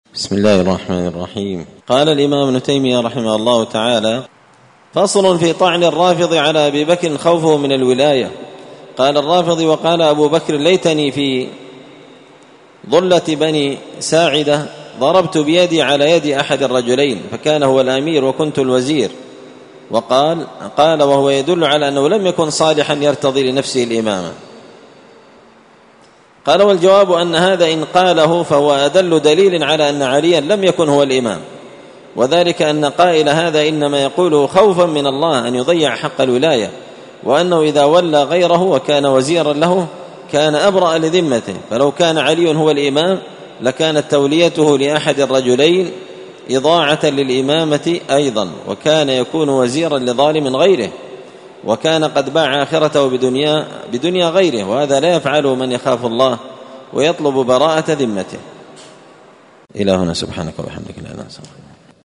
الدرس الثاني بعد المائة (102) فصل طعن الرافضي على أبي بكر خوفه من الولاية
مسجد الفرقان قشن_المهرة_اليمن